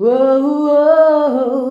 UOUOUOH 1.wav